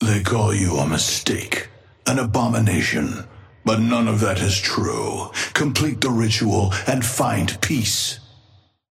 Patron_male_ally_viscous_start_03.mp3